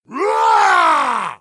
roar_02
Category: Games   Right: Personal